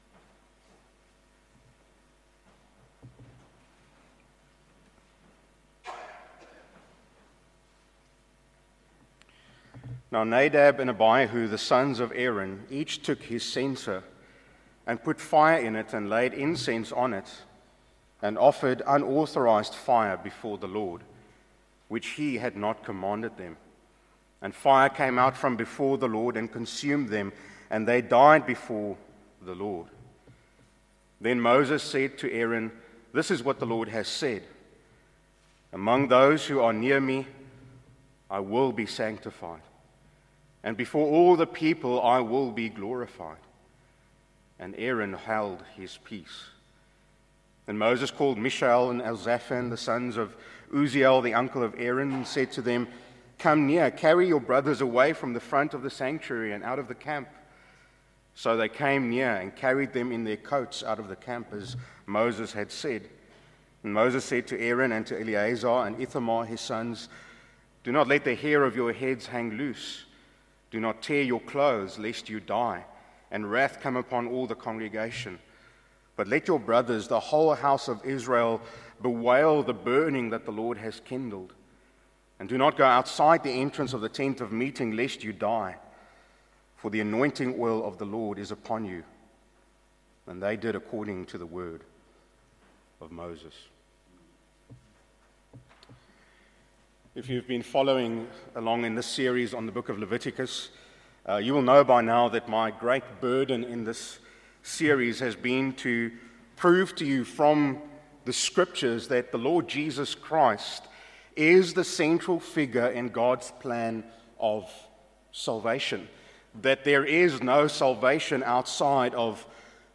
A message from the series "The Gospel in Leviticus."